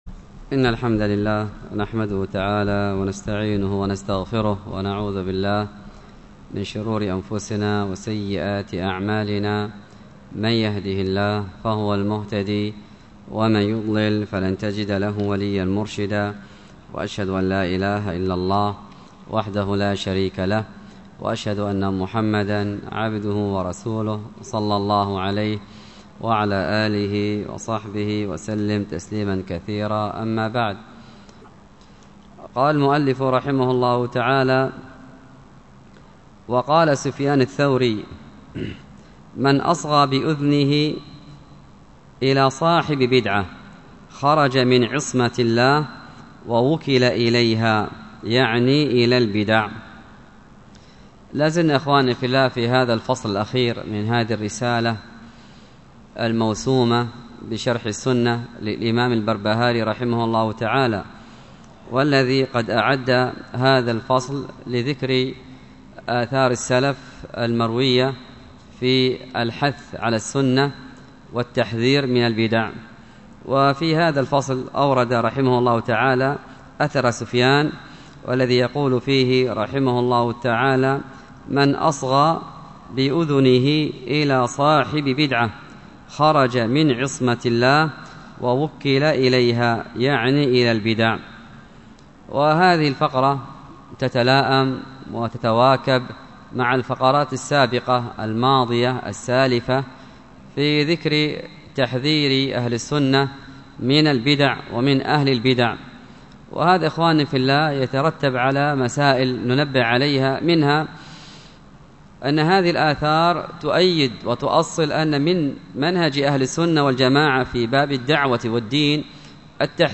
الدرس في شرح كتاب ضوابط الجرح والتعديل 26